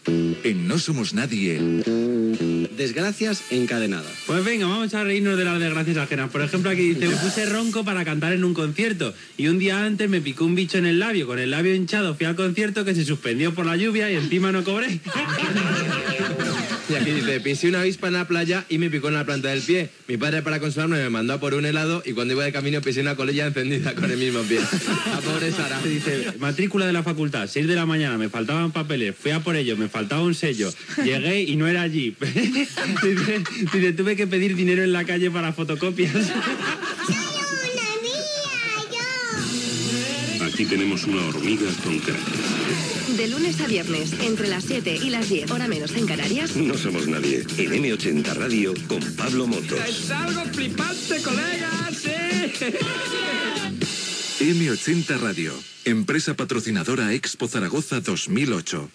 indicatiu del programa i de l'esmissora